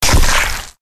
splatter.mp3